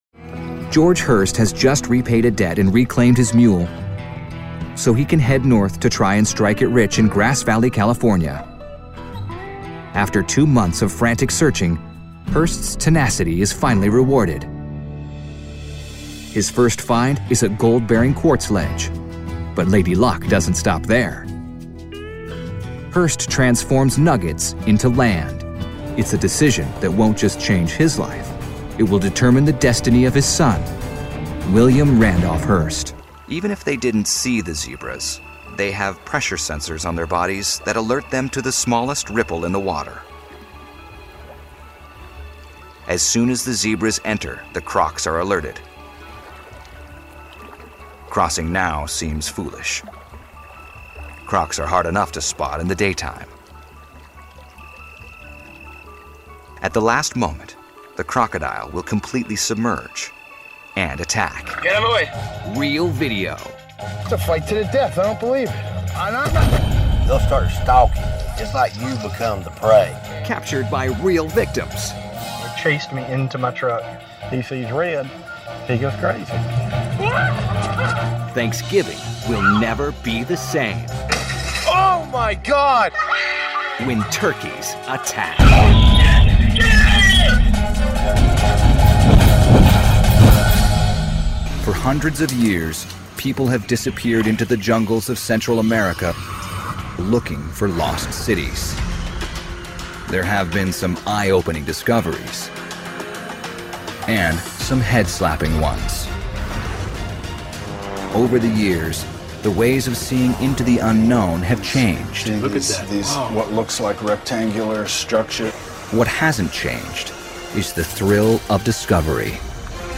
Contemporary, Real, Persuasive.
Narration